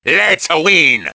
One of Wario's voice clips in Mario Kart Wii